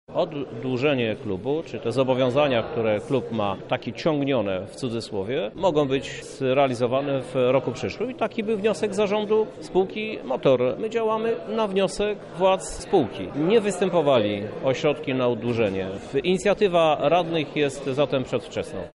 – Te 600 tysięcy złotych to jest dopełnienie tegorocznego budżetu – wyjaśnia prezydent Krzysztof Żuk: